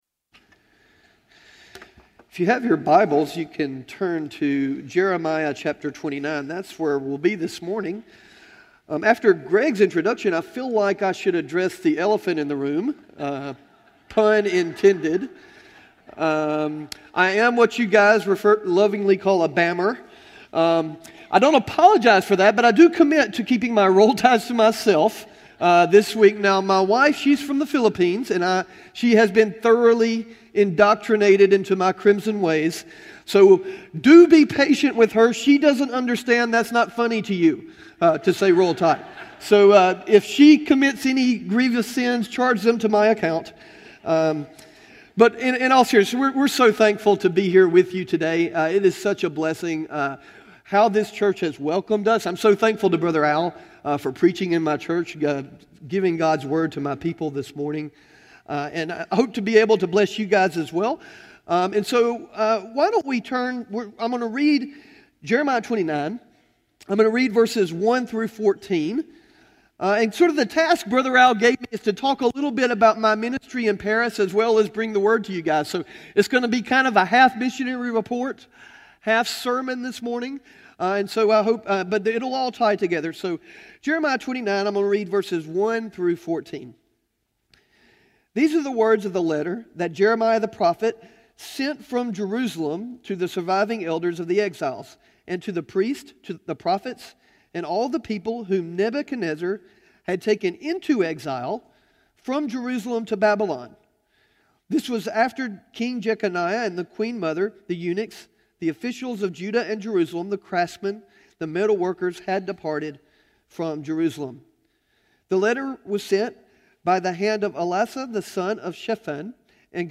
Stand Alone Sermons
Service Type: Sunday Morning